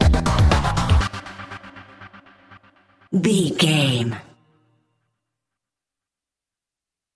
Aeolian/Minor
drum machine
synthesiser
electric piano
Eurodance